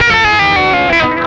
MANIC GLISS3.wav